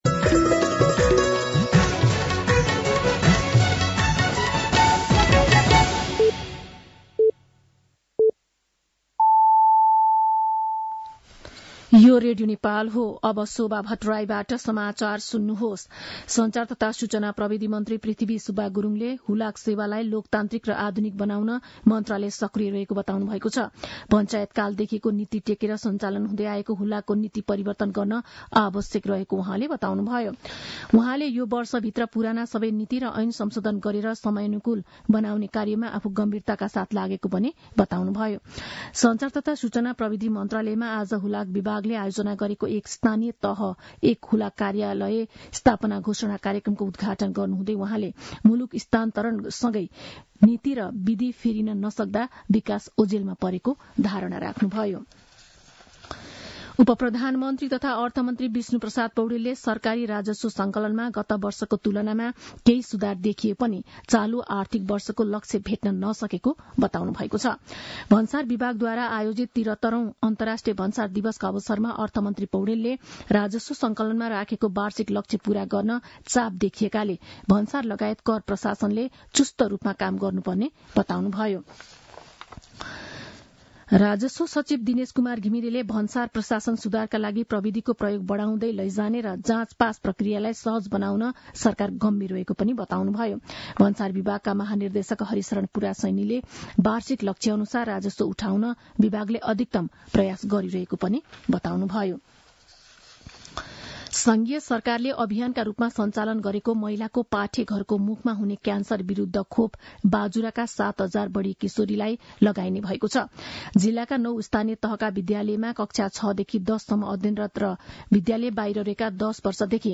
साँझ ५ बजेको नेपाली समाचार : १४ माघ , २०८१
5-pm-news-4.mp3